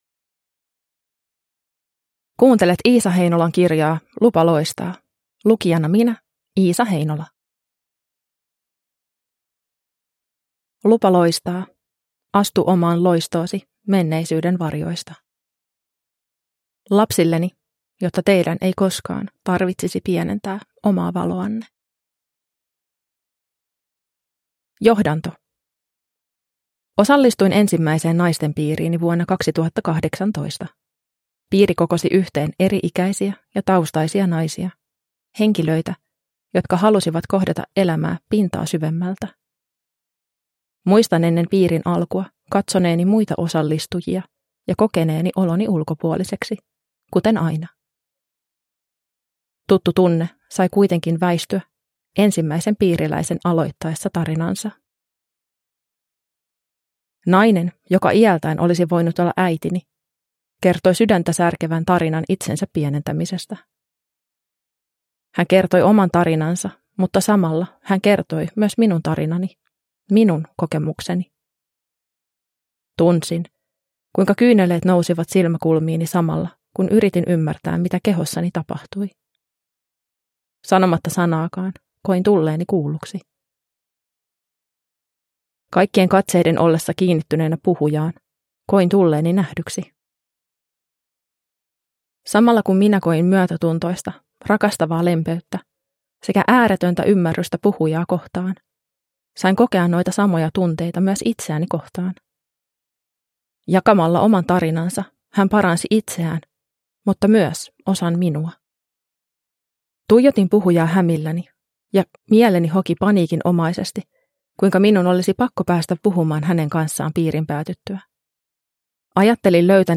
Lupa loistaa – Ljudbok – Laddas ner